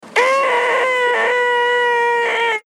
Play, download and share The Most Annoying Sound Pt 2 original sound button!!!!
the-most-annoying-sound-pt-2.mp3